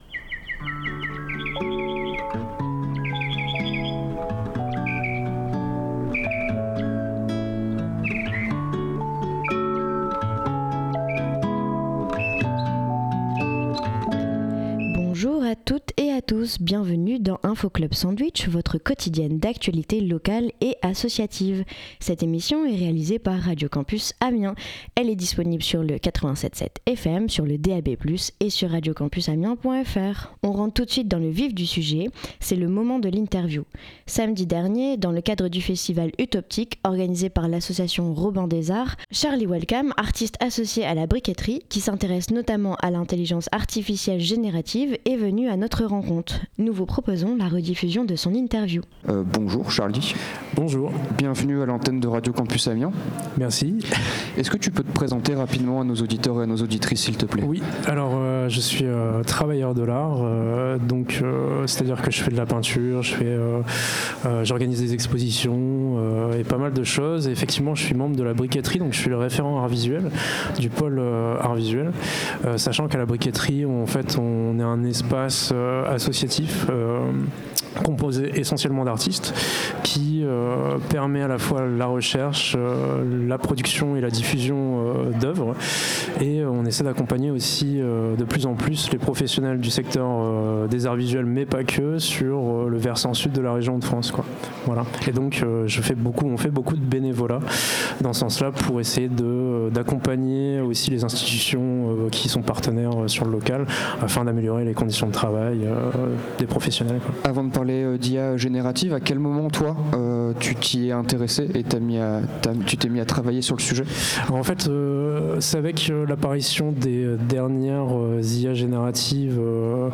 Pour l’interview du jour, on vous propose une rediffusion d’une interview réalisée pendant notre direct du Festival Utoptique de ce samedi 15 mars.